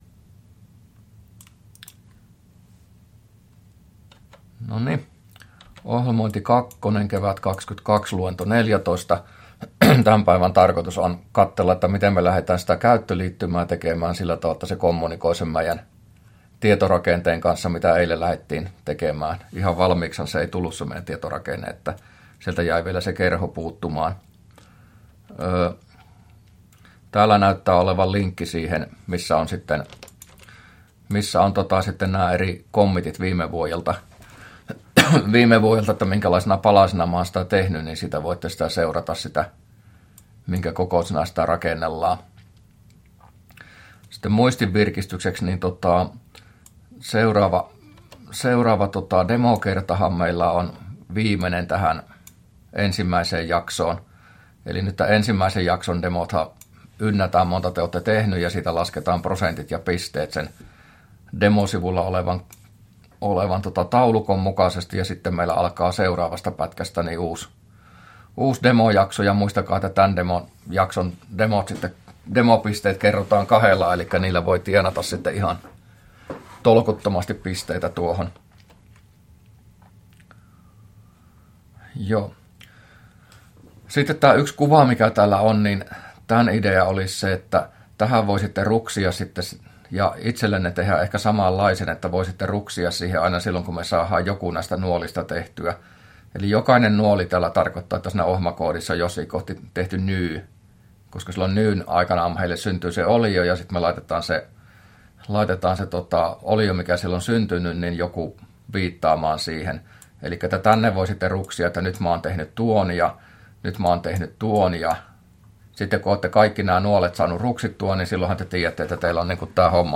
luento14a